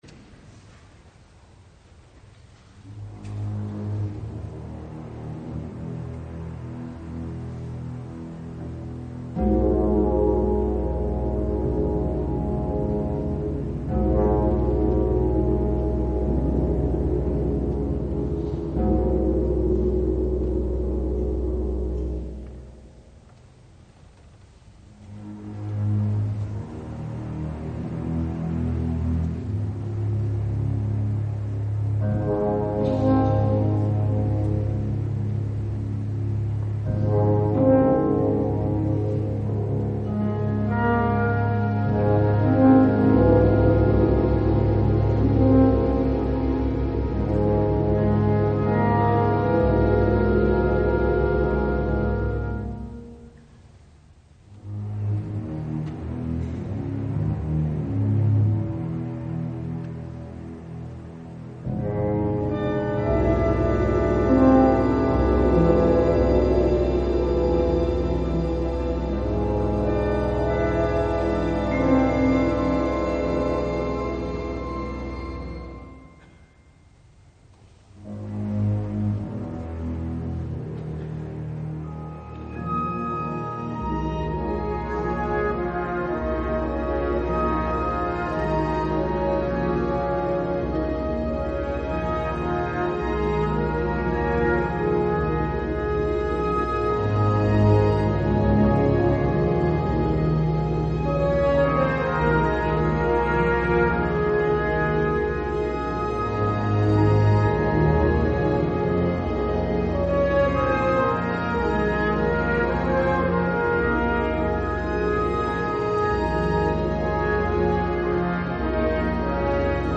(指揮)ヘスス・ロペス・コボス
(管弦楽)ＮＨＫ交響楽団 　　 通して聴く